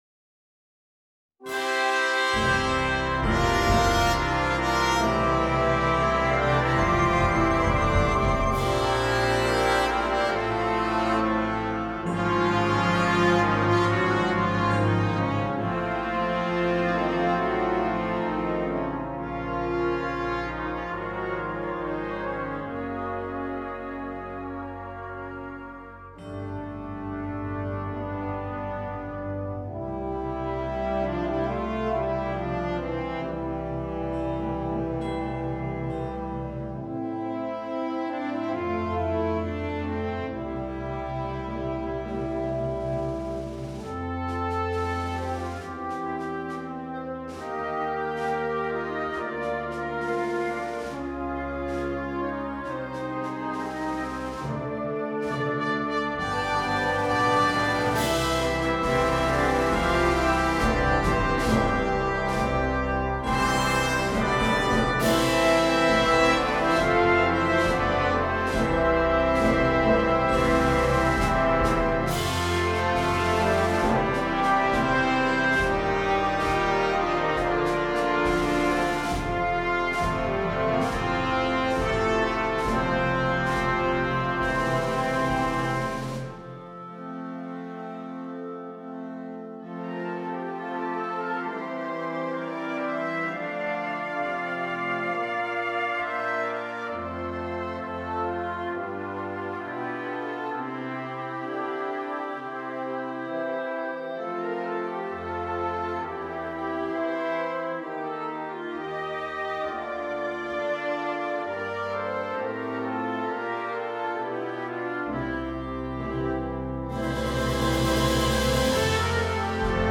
Instrumentation: Bagpipes with Wind Band